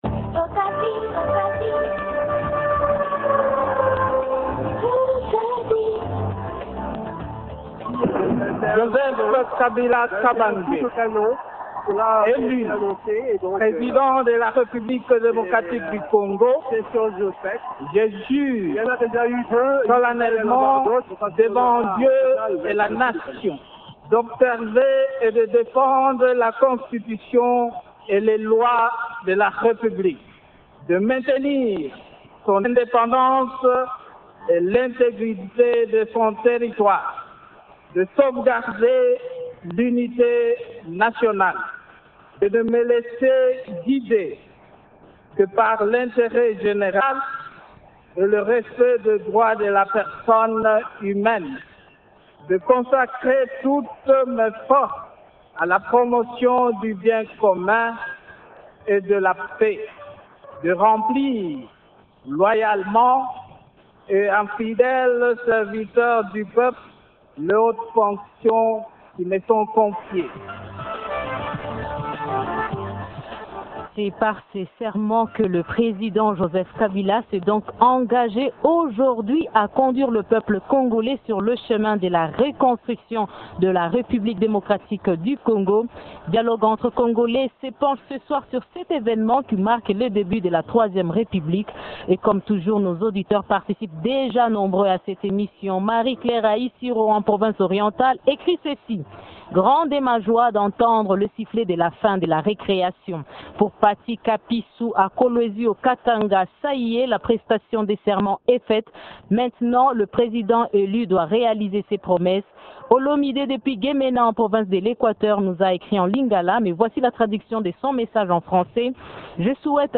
Le débat tourne autour de deux questions, à savoir :rn- Comment s’est déroulée cette cérémonie?